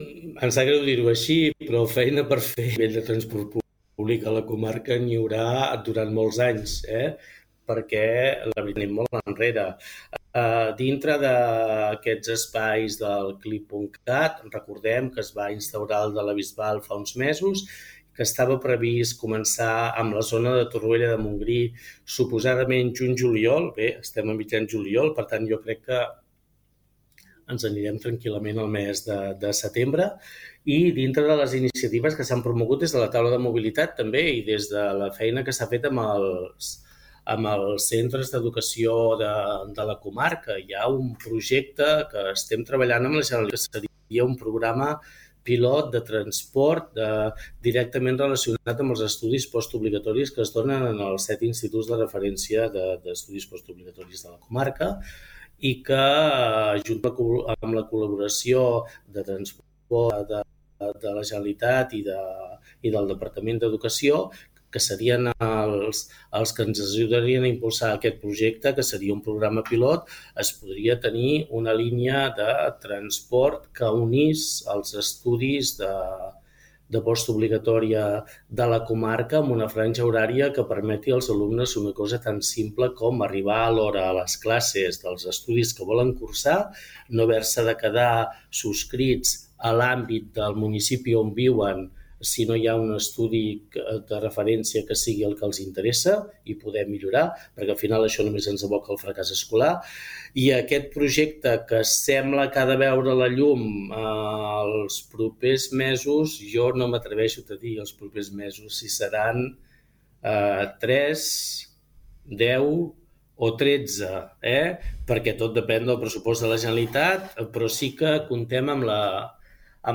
En una entrevista a Ràdio Capital, ha detallat els reptes principals del seu mandat: impulsar l’economia blava, consolidar un hub cultural comarcal, millorar la mobilitat i afrontar la gestió dels residus a la comarca.